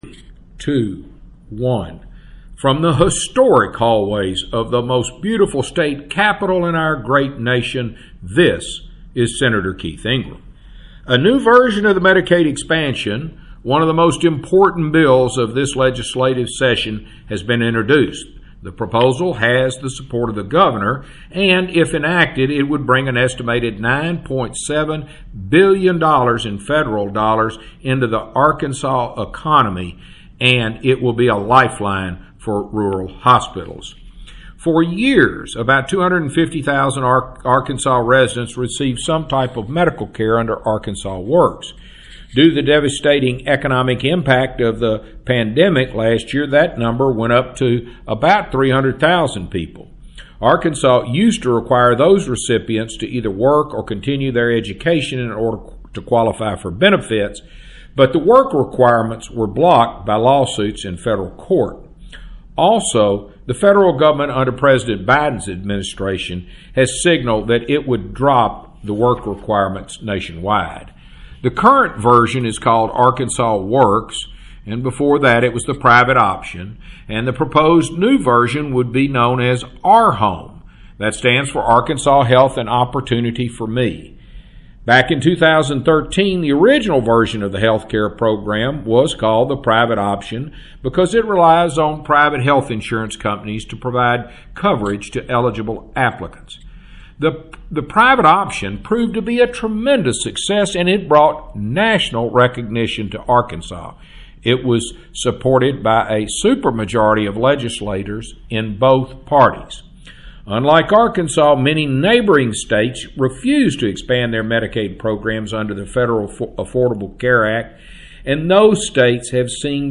Weekly Address – March 4, 2021 | 2021-03-03T21:28:10.135Z | Sen.